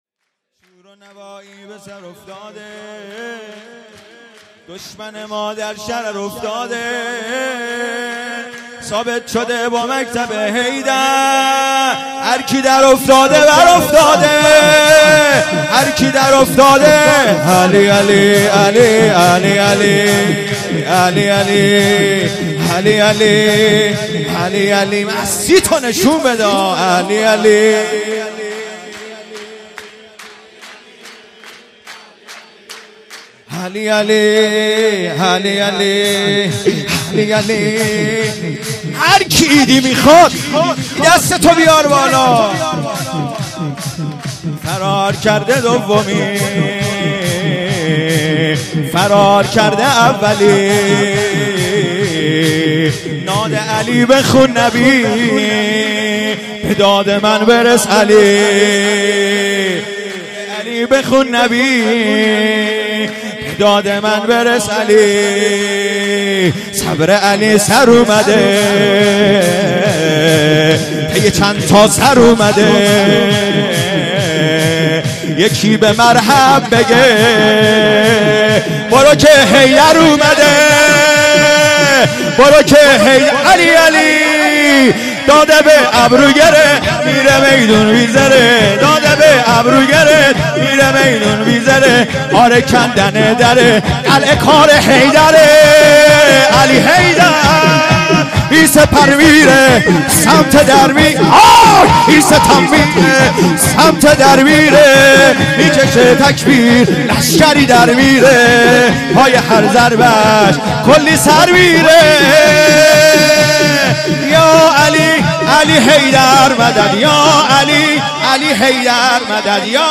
سرود | باز نوایی به سر افتاده
عیدانه نیمه شعبان